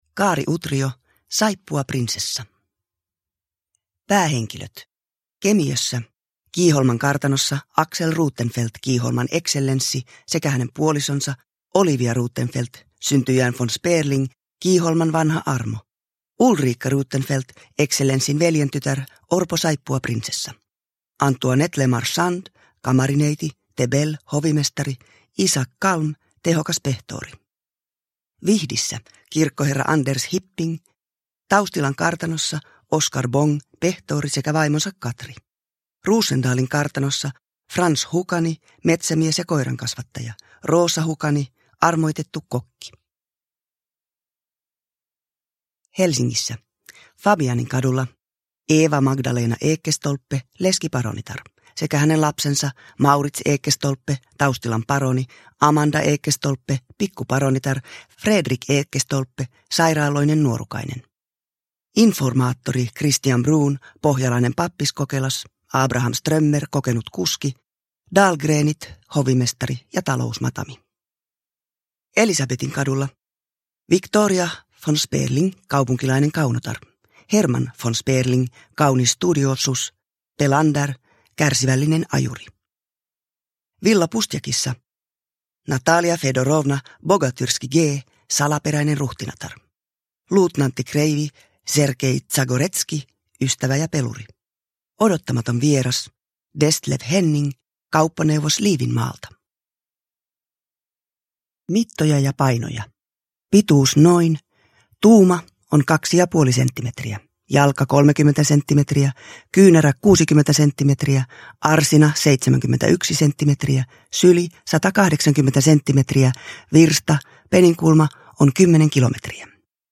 Saippuaprinsessa – Ljudbok
Uppläsare: Susanna Haavisto